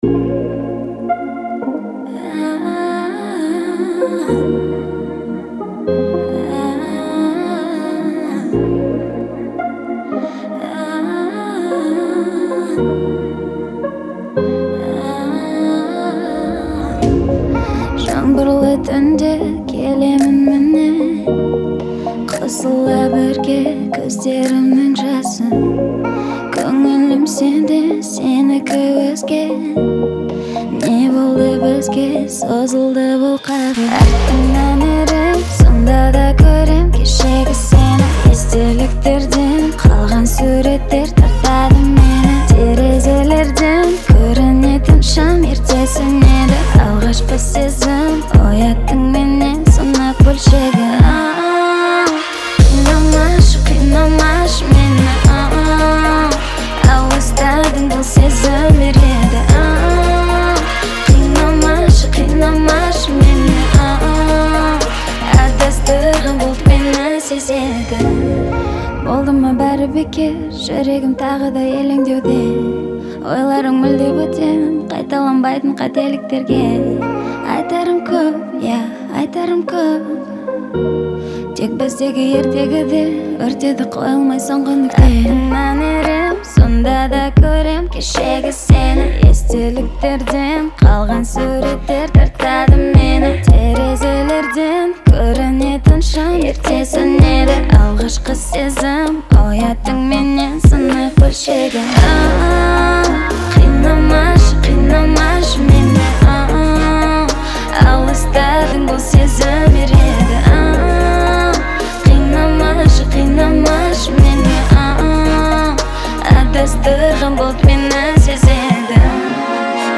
это яркая и энергичная композиция в жанре поп